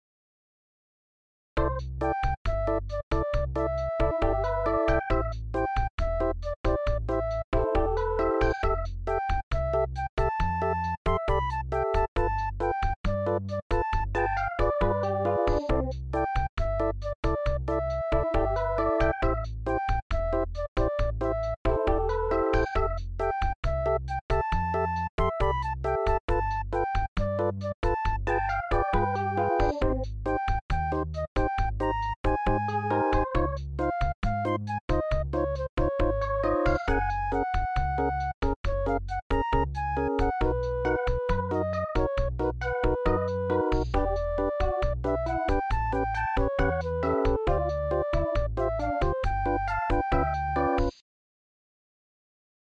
An extremely cute little bossa nova ditty.